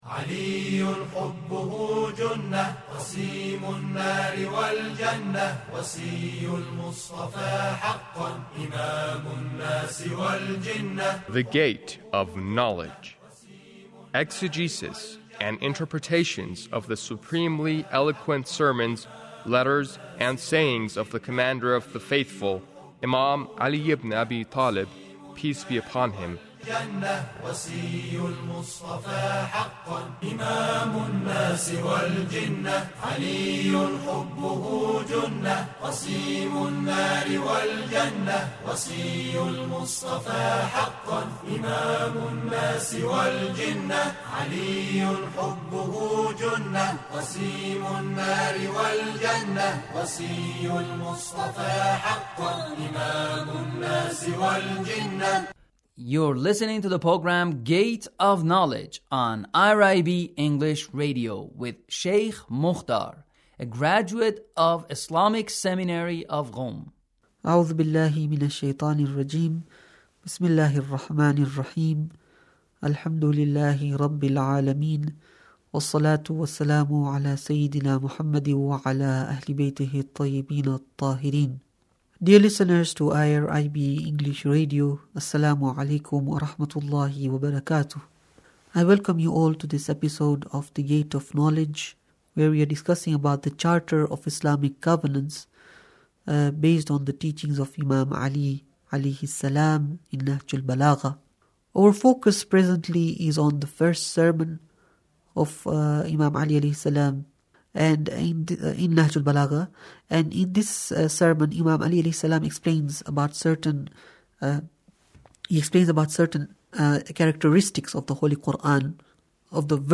Sermon 1 - The Koran as a revolutionary book 18